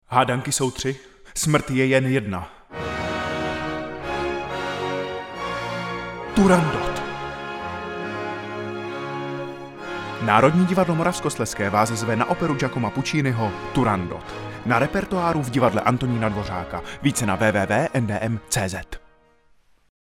Hudebně se v ní spojuje bohatá orchestrace, brilantní vokální melodika, jakož i dobová evropská fascinace východními kulturami. Opera odehrávající se v čínském císařském městě Pekingu vypráví příběh tajemné princezny Turandot, jež své nápadníky staví před smrtící výzvu: vyřešit tři hádanky, nebo čelit popravě.
Uvádíme v italském originále s českými a anglickými titulky